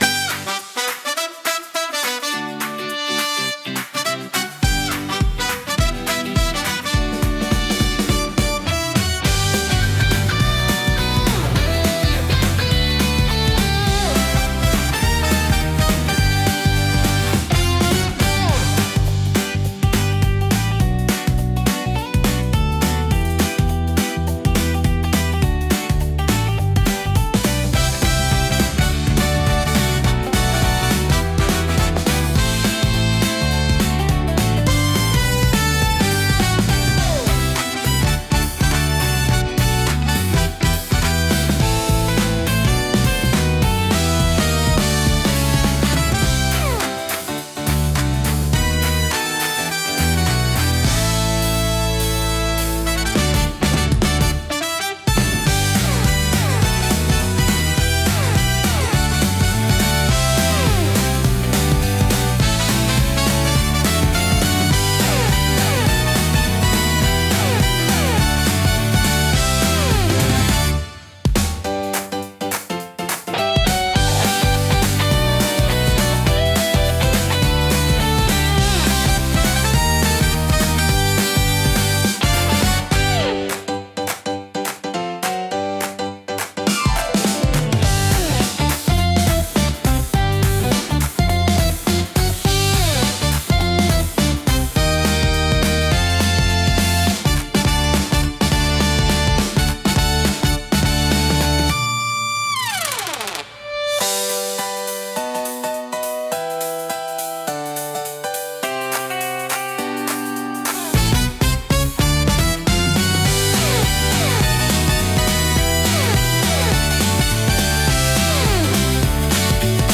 軽快なリズムとポップなメロディが楽しい雰囲気を作り出し、買い物やショッピング体験を爽やかに演出します。
明るくハッピーな曲調が特徴のジャンルです。